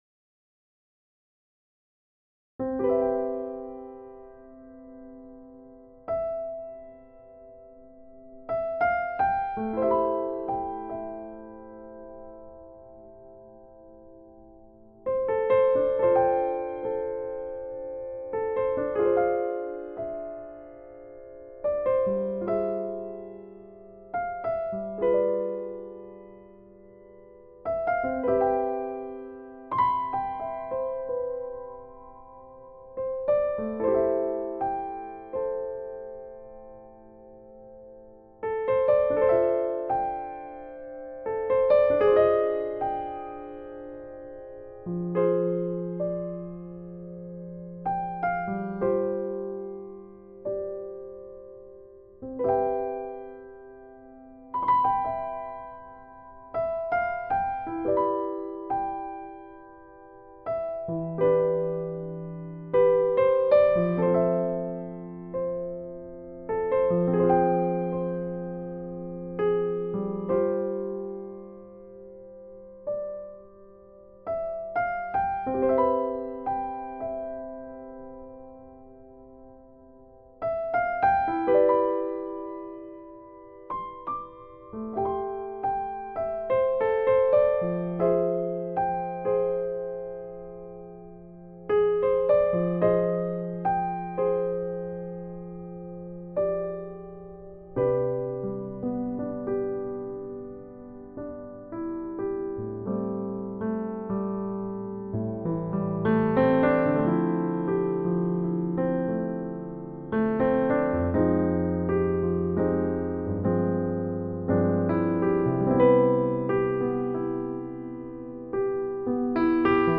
I immediately got out of bed after reading your email, went downstairs to my workstation, and recorded a little piano improvisation as a gift for you. I sought to capture in the music your warm and gentle demeanour, as well as the highly creative, ad libitum, welcoming atmosphere at the shooting party. The music was done in one take, completely free flowing, in the same spirit as the Drama Mama portraits I experienced with you.